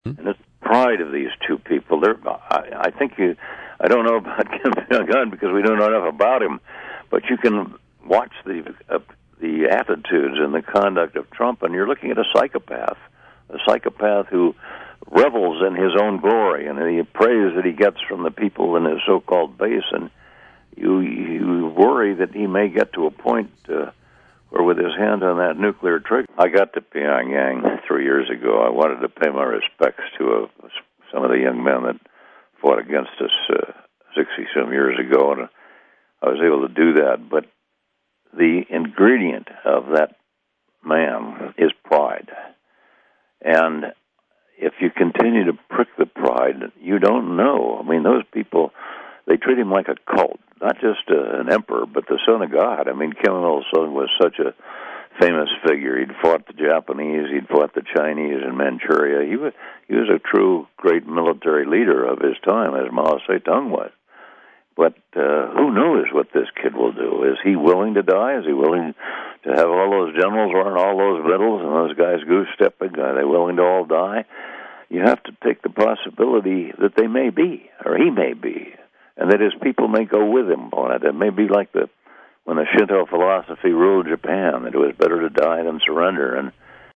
In-Depth Interview: Korea Vet, Former Congressman Pete McCloskey Shares Concerns About Trump vs. Kim
In this wide-ranging conversation, we also talk about drones, war powers, weak Democrats, and Pete’s 90th birthday on September 29.